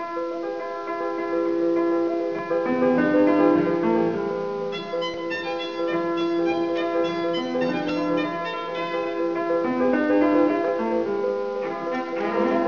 Vln, Vc, Pno   [Performance]